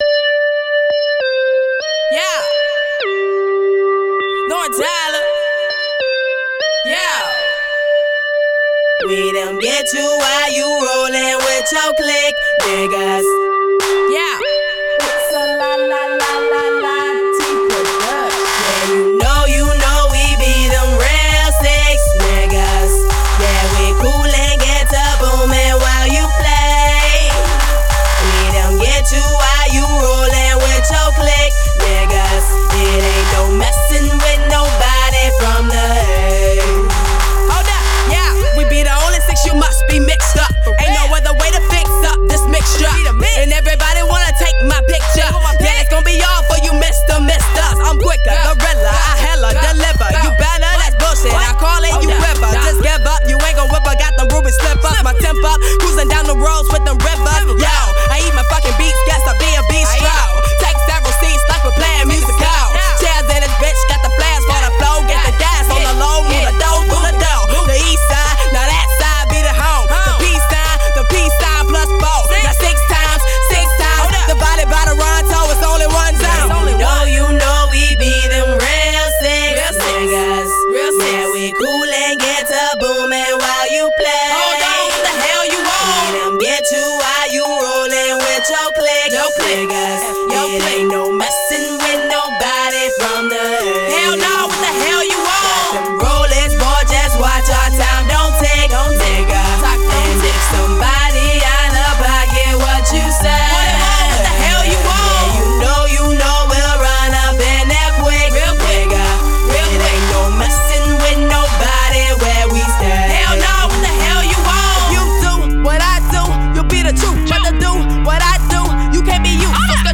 Hiphop
New Club Banger